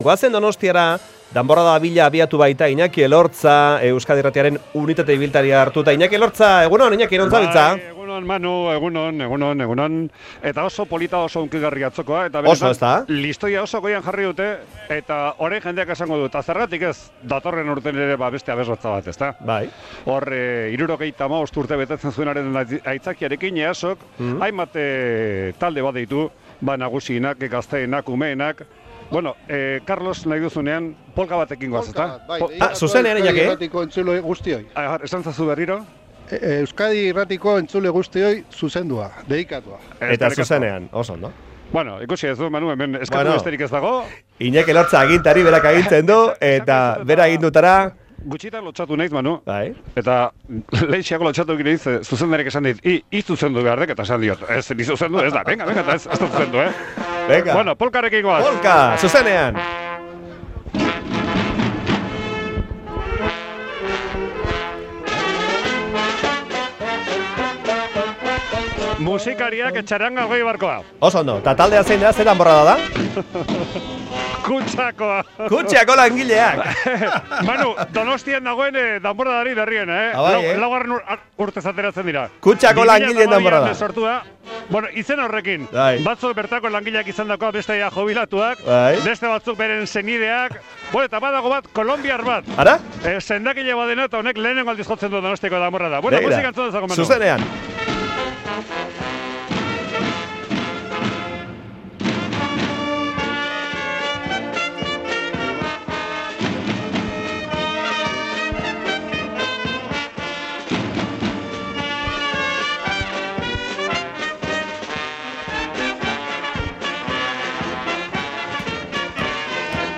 San Sebastian eguneko giroa, kaletik zuzenean
Musikari eta danbor joleen atzetik dabil Euskadi irratia Donostiako kaleetako giroa bertatik bertara eta zuzenean helaraziz.